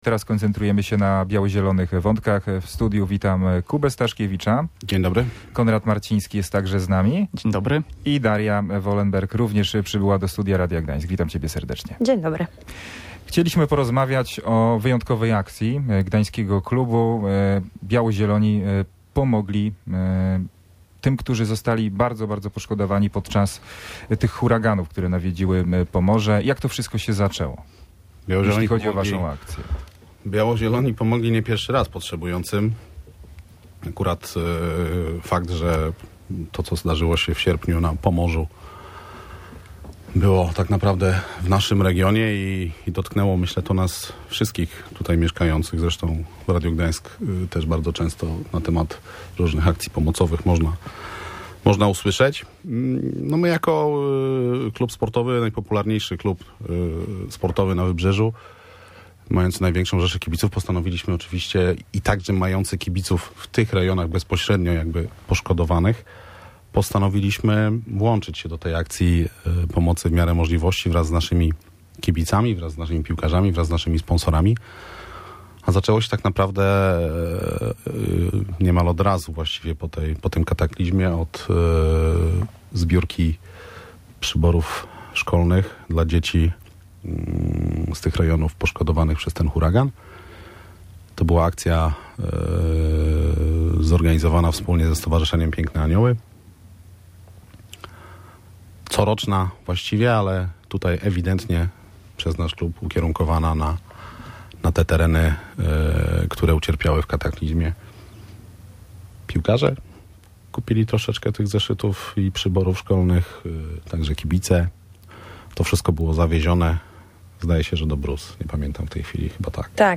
w studiu Radia Gdańsk